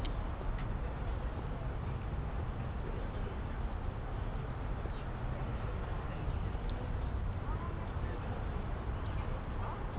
noise.wav